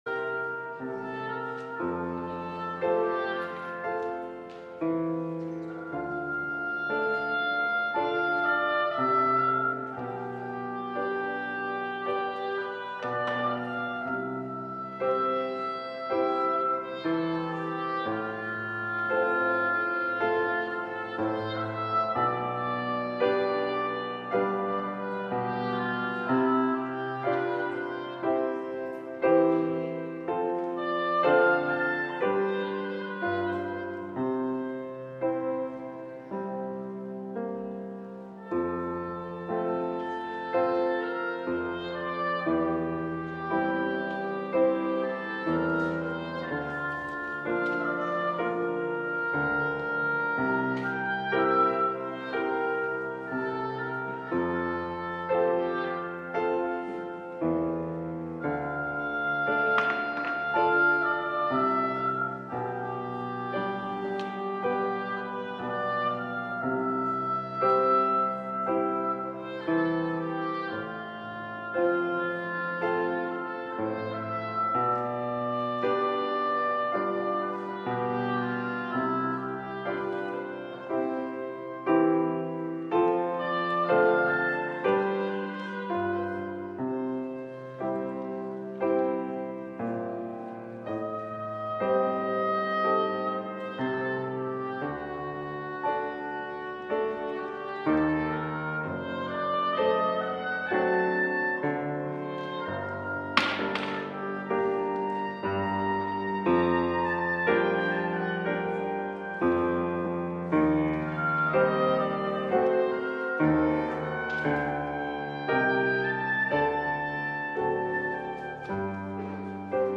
LIVE Evening Worship Service - Ephphatha! Be Opened!
LIVE Evening Worship - 2026-02-08